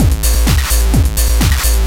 DS 128-BPM A1.wav